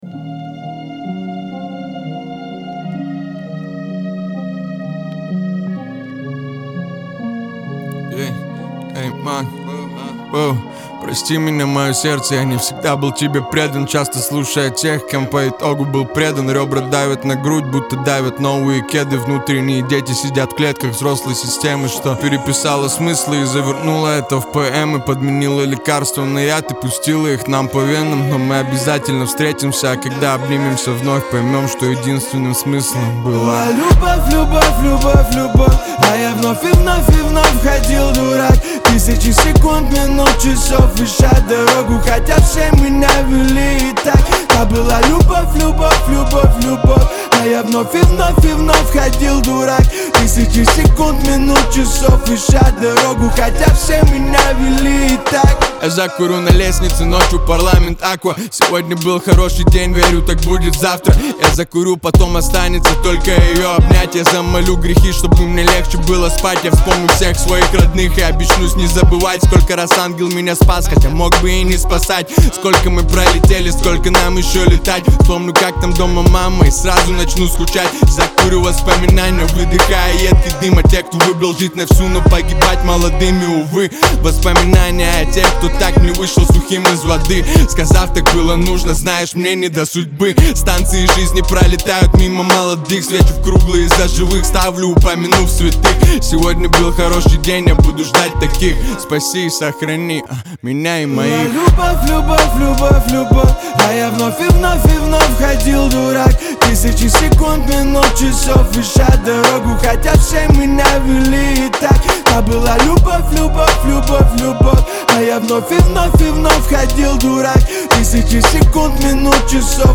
Жанр: rusrap
Rap, Hip-Hop